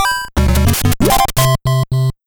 AchievementUnlock.wav